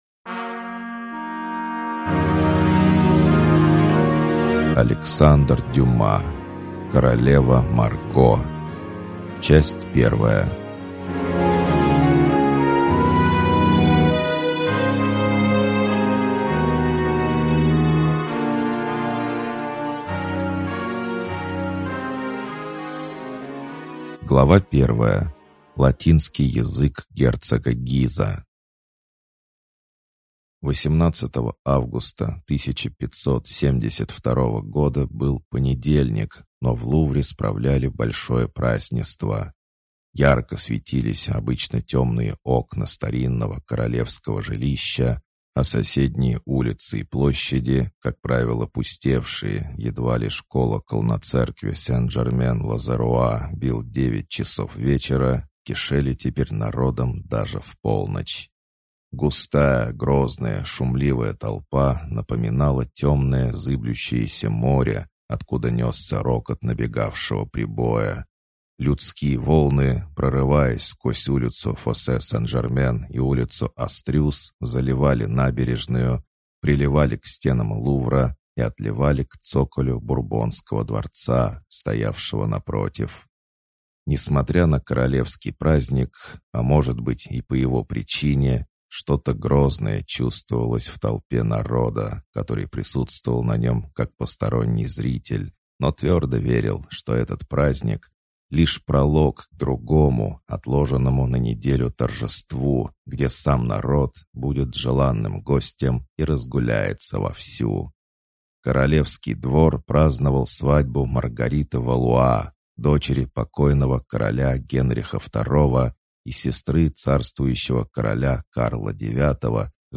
Аудиокнига Королева Марго | Библиотека аудиокниг